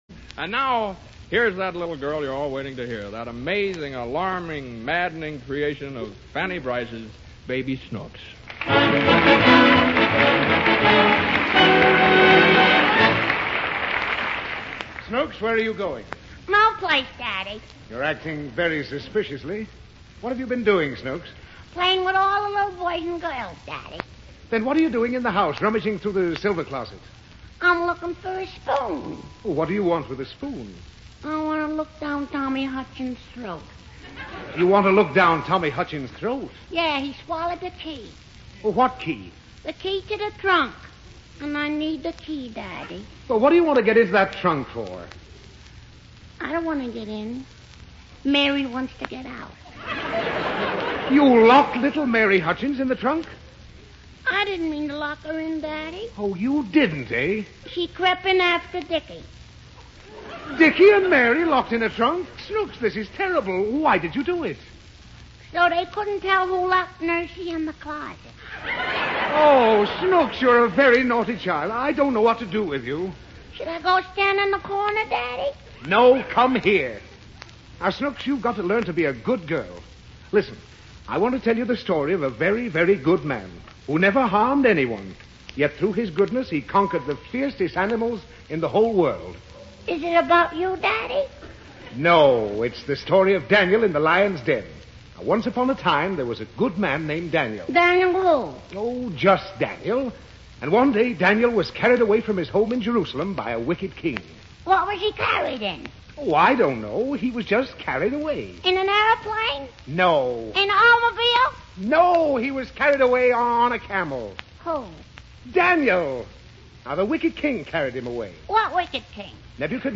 The Baby Snooks Radio Program, Starring Fanny Brice
Daniel in the Lion's Den with intro by Jimmy Stewart